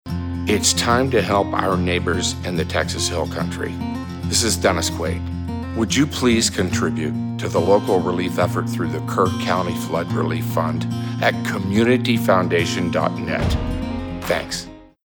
At the invitation of the Texas Radio Hall of Fame, actor/musician Dennis Quaid, a native Texan, offered his voice in support of the flood relief efforts in the Texas Hill Country.
Featuring Dennis Quaid